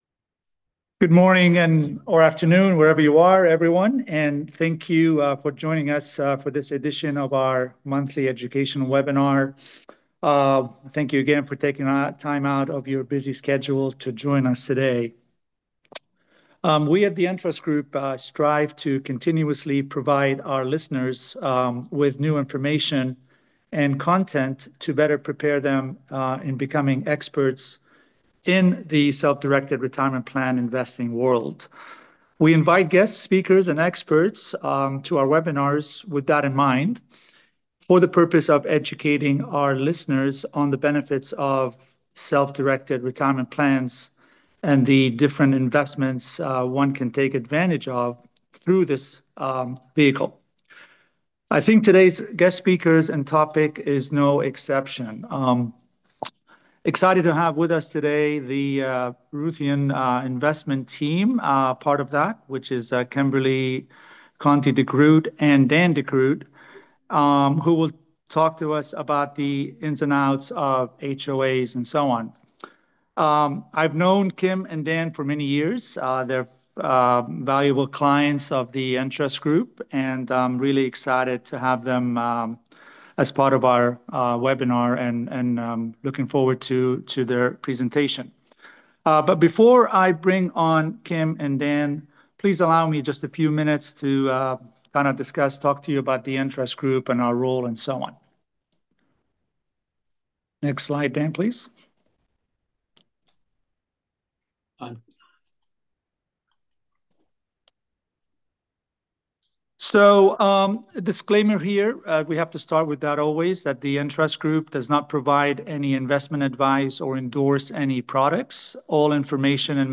Webinar_April_2025_Audio_Replay.m4a